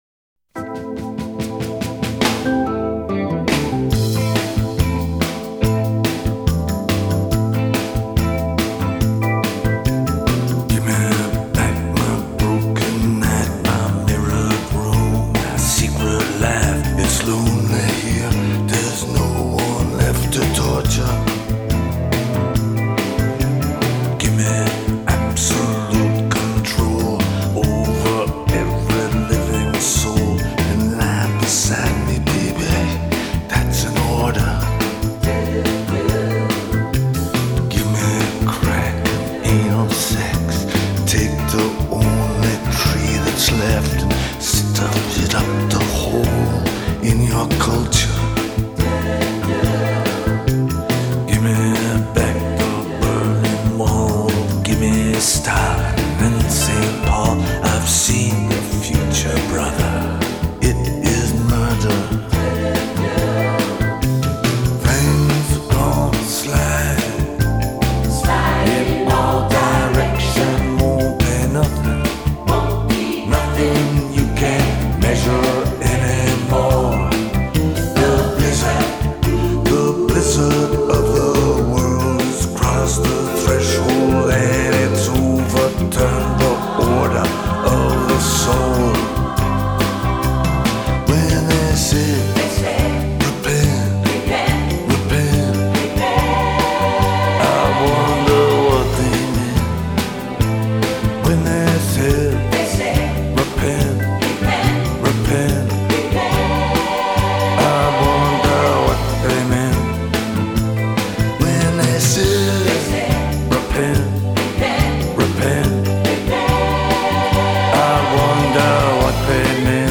Soul музыка